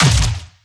Plasma Rifle
LASRGun_Plasma Rifle Fire_06_SFRMS_SCIWPNS.wav